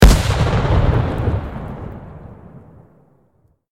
medium-explosion-2.ogg